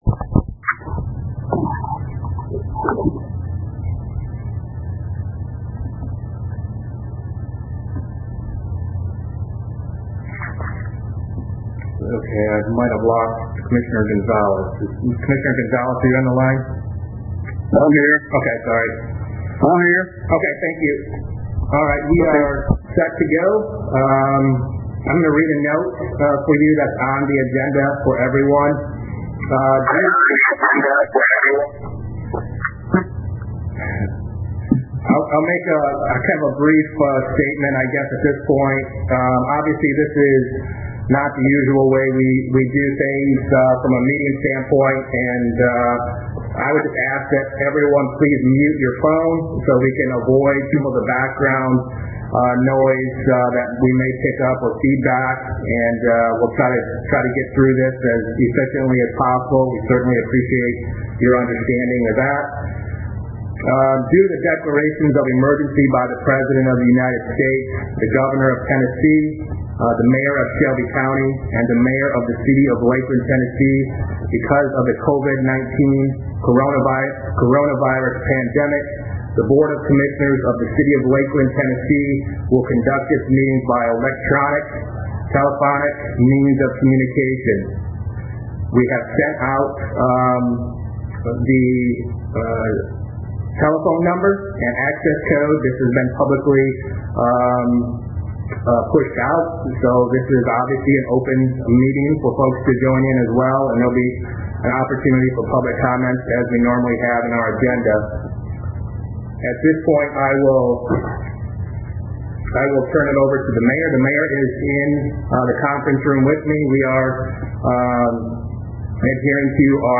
(Audio of the BOC meeting)
The BOC (Board of Commissioners) convened in a virtual meeting Thursday night (4.9.20) and approved a partnership with a local church to serve the needy and approved by 3-2 a first reading of an ordinance relating to appeals on the Design Review Commission (DRC).